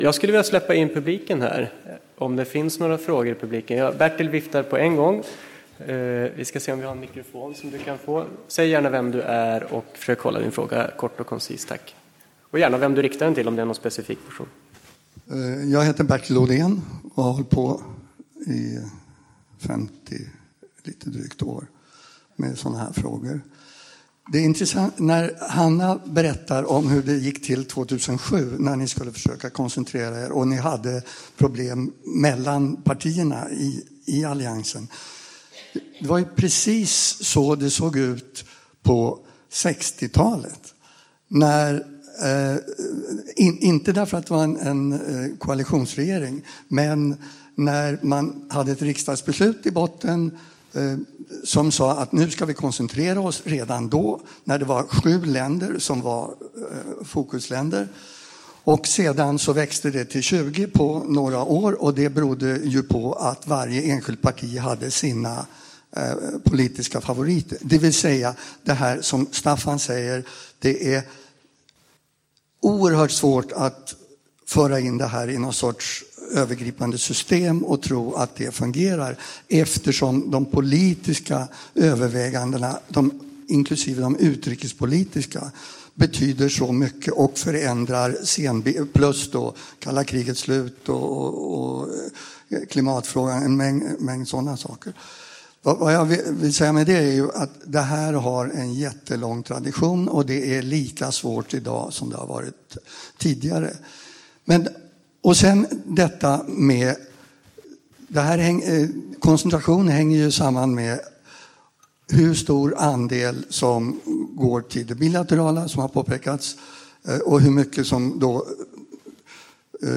Paneldiskussion